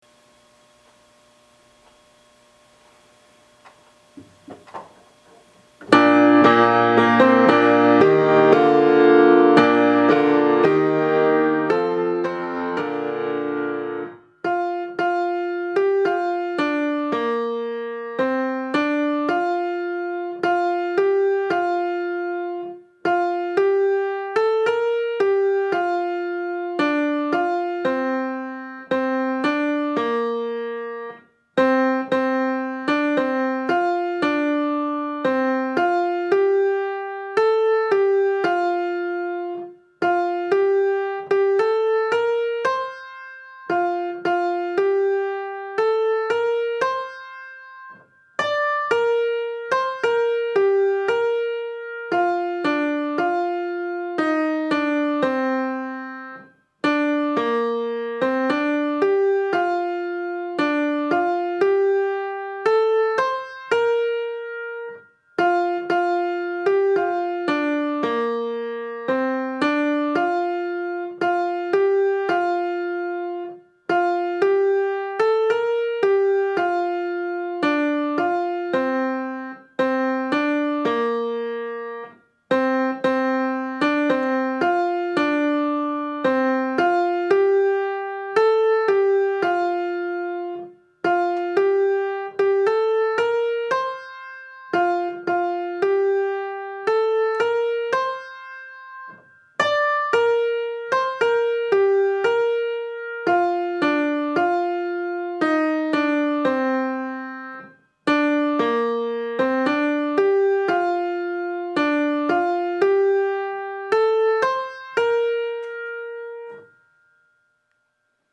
松ヶ崎小学校　校歌（旋律）